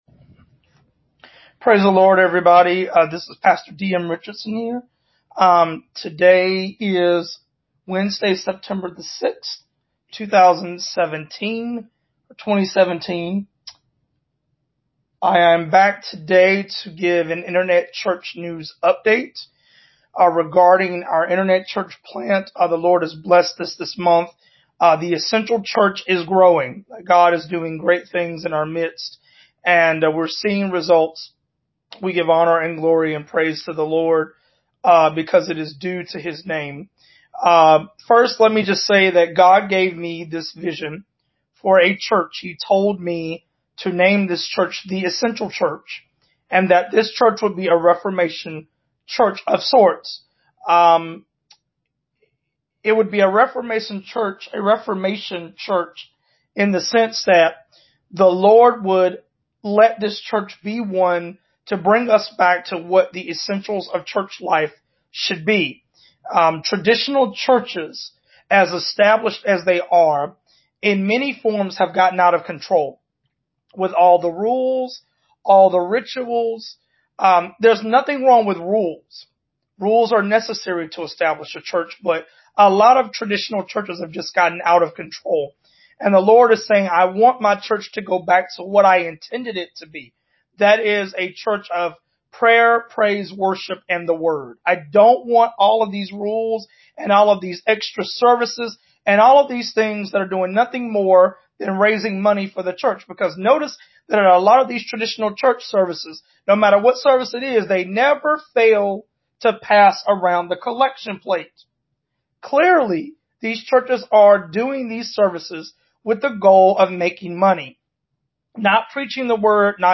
The audio recording above pertains to a report regarding The Essential Church internet church news, an update on the events going on in our church.